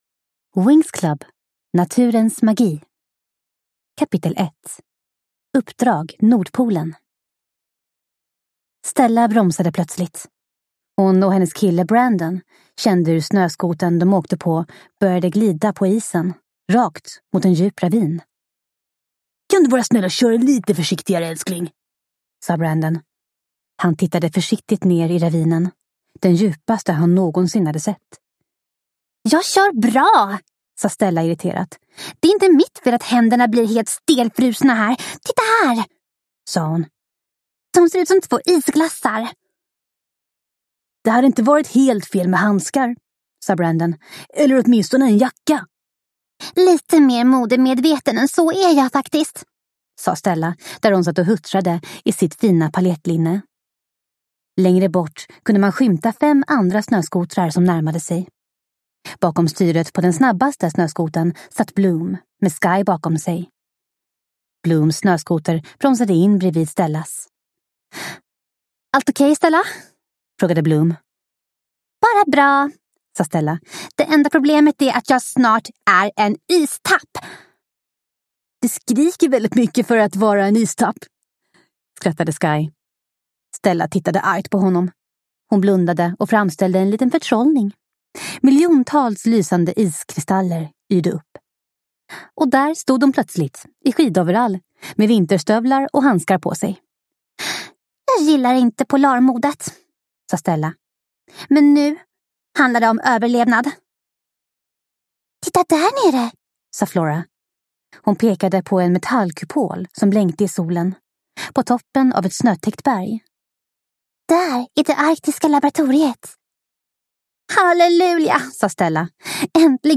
Winx Club: Naturens magi – Ljudbok – Laddas ner